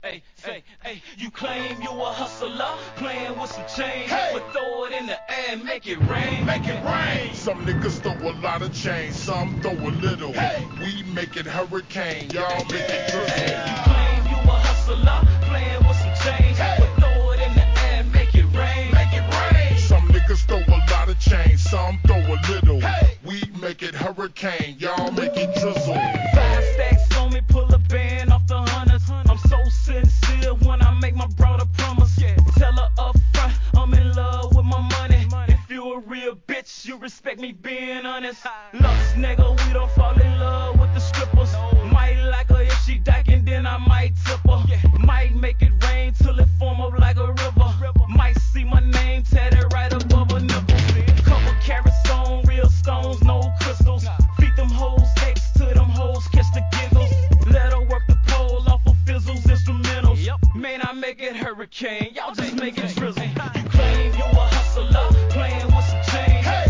HIP HOP/R&B
イントロの落雷と雨の音から一気にタッタカタンタン♪スネアの連打からシンセと、ピアノの上音がサウスファン直球の一曲!!